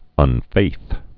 (ŭn-fāth)